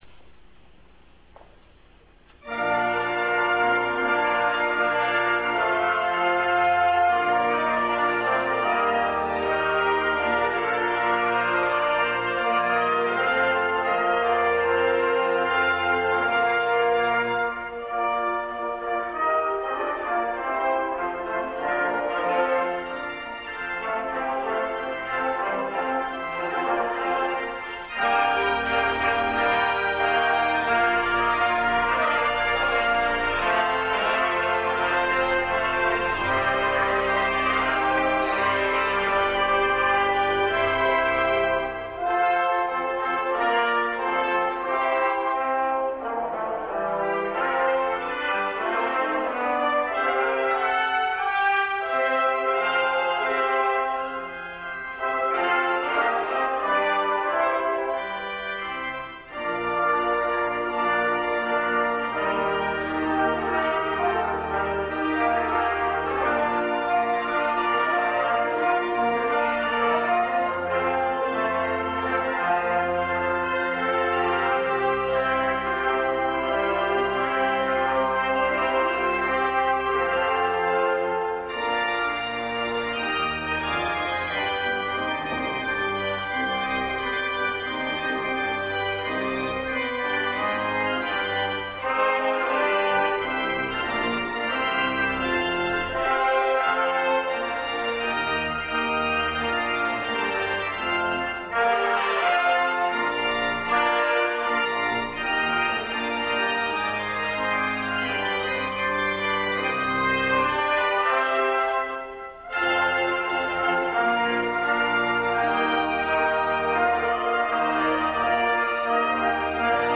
Trumpet Voluntary - Avatar Brass & Brass Renaissance at our November, 1997 concert. (400Kb) Canzon Duodecimi Toni (with organ) - Brass Renaissance at our November, 1997 concert.